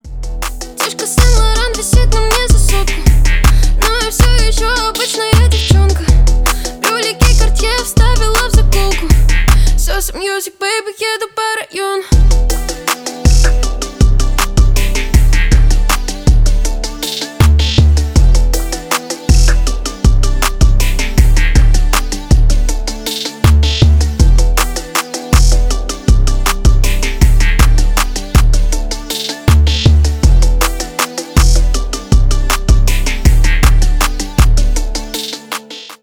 • Качество: 320, Stereo